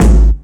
GS Phat Kicks 014.wav